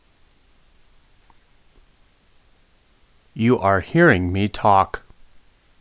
Assistance with hiss diagnosis
I’m having a consistent problem with hiss on my recording.
I’ve played it back on a few different computers, and through the speakers the hiss is not very evident but it’s immediately apparent when listening through headphones.
I’m using a Samson G Track USB mic.
Indeed your sample is noisier than I would expect.